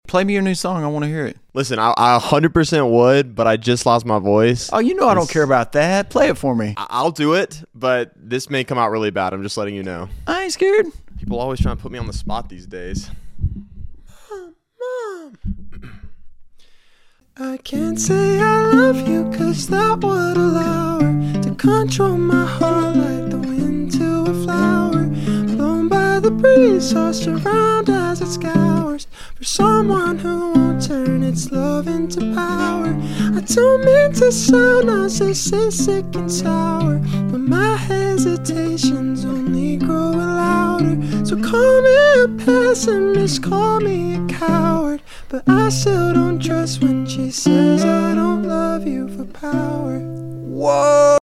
Voice still isn’t 100% there, but I think this worked out fine.